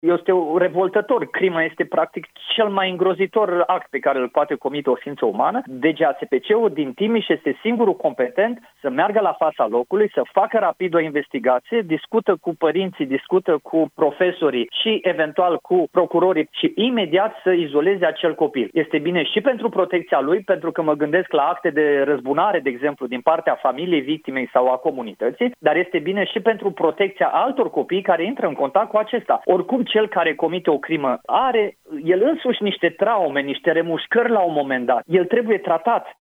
Cristi Danileț, fost judecător: „Cel care comite o crimă are el însuși niște traume, niște remușcări la un moment dat. El trebuie tratat”